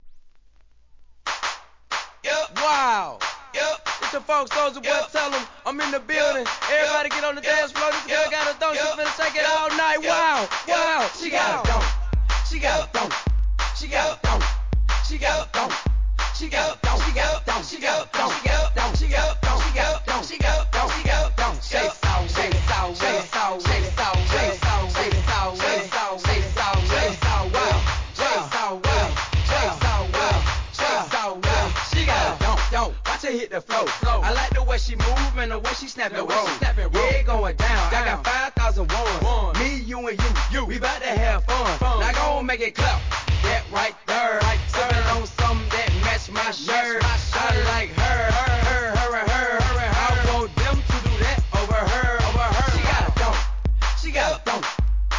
HIP HOP/R&B
音数は極端に少ないものの派手に打ち鳴らすフロア対応の逸品!!